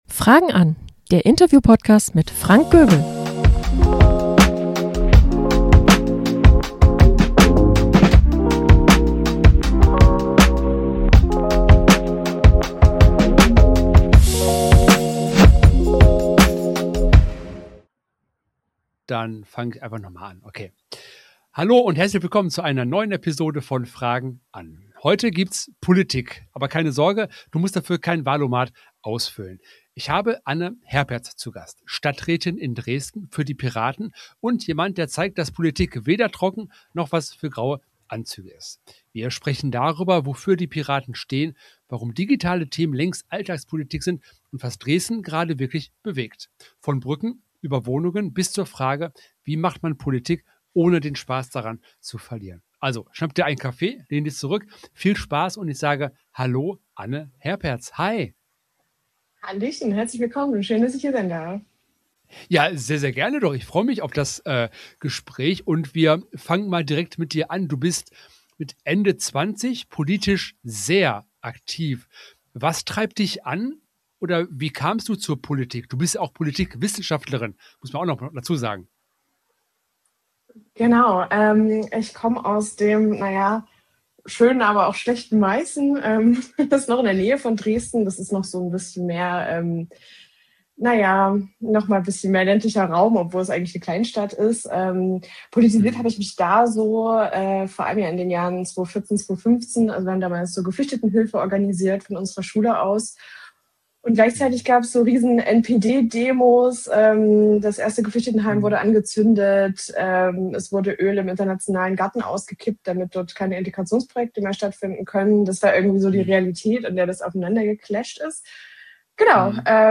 Außerdem werfen wir einen Blick auf aktuelle Themen, die Dresden bewegen: bezahlbares Wohnen, Infrastruktur, politische Kultur und die Debatte rund um die Zukunft der Carolabrücke. Ein Gespräch über Haltung, Stadtpolitik und darüber, wie Politik greifbar bleiben kann.